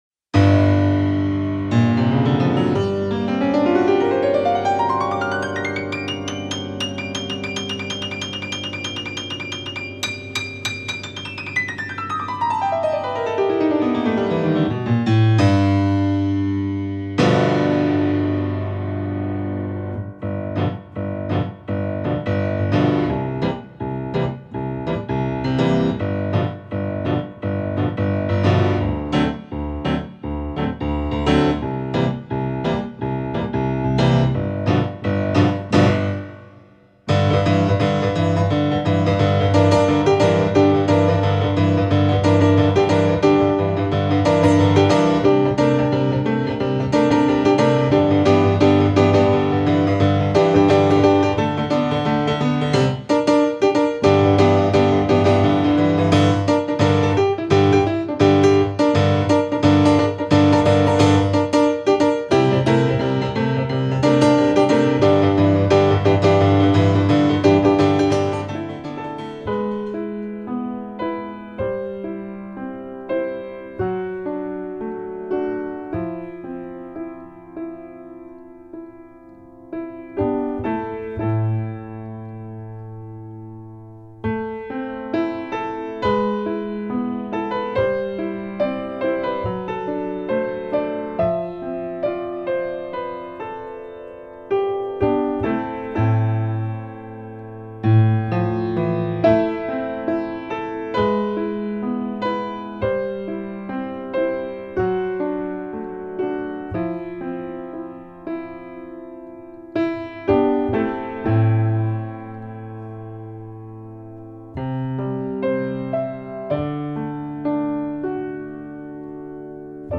Studio di registrazione e sale prova a Capriolo, Brescia.